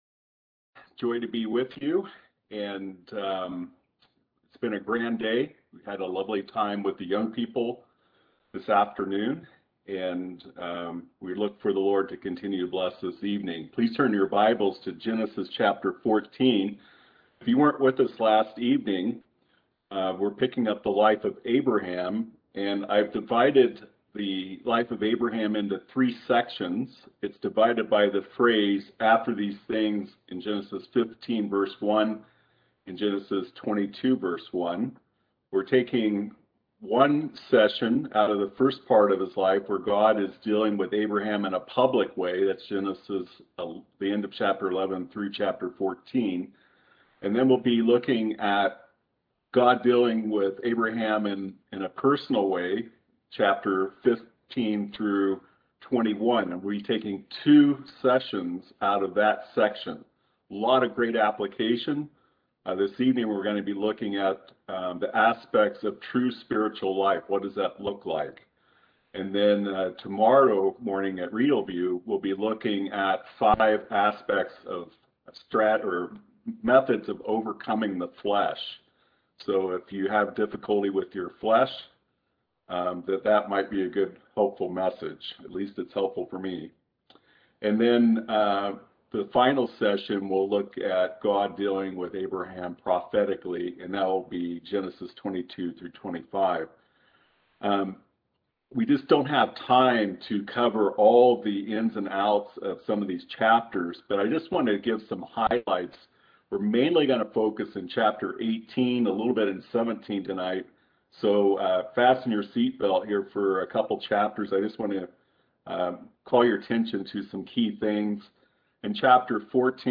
Series: Easter Conference Passage: Genesis 14-21 Service Type: Seminar